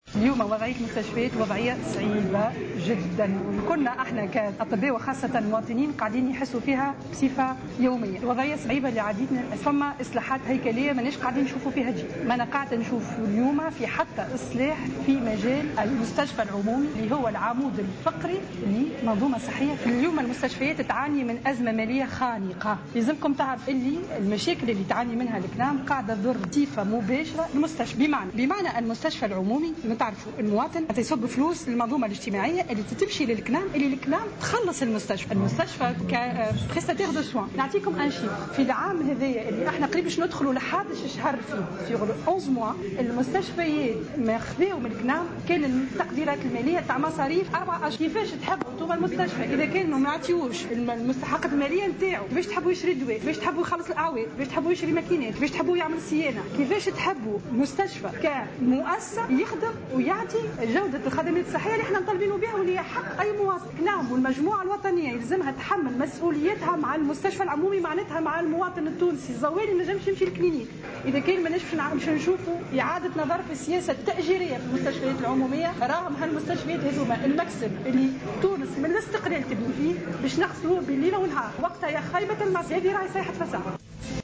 و أضافت في تصريح لمراسلة "الجوهرة أف أم" اليوم على هامش ندوة وطنية حول "مستشفى الغد في تونس" أن الأزمة التي يمر بها الصندوق الوطني للتأمين على المرض أثرت بشكل كبير على وضعية المستشفيات العمومية. و أوضحت أن تأخر "الكنام" في تسديد مستحقات المستشفيات أثر على توازناتها المالية.